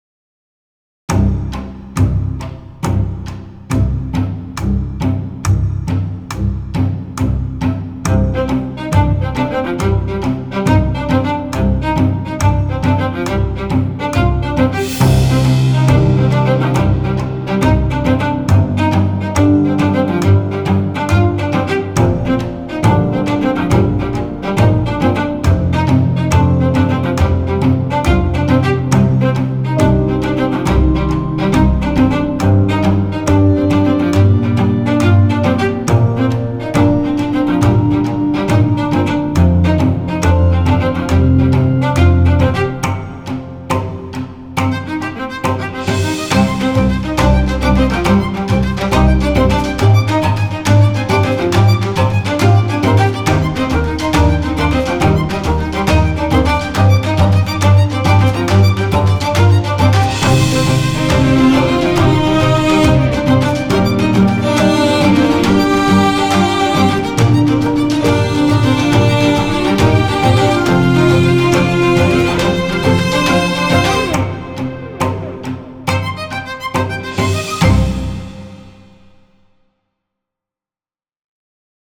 ACTION – EPIC
String Small Ensamble